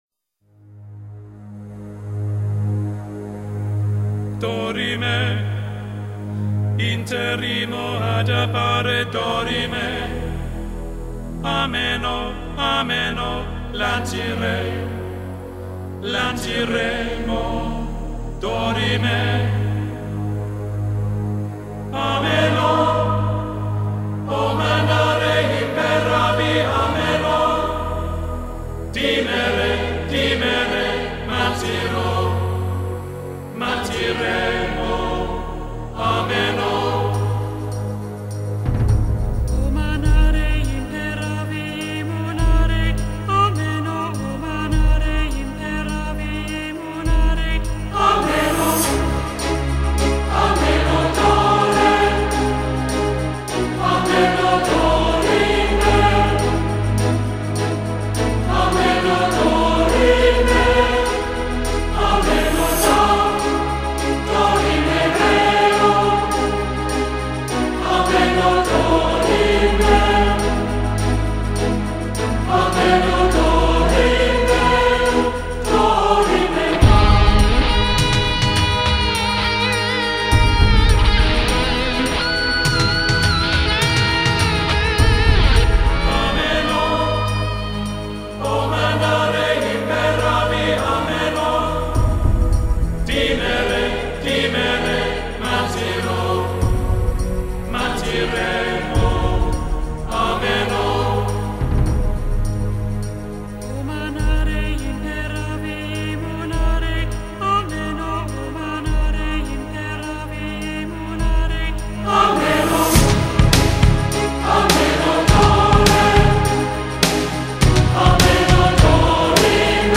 大气.听了很振奋..谢了
很有气势的一首精品歌曲！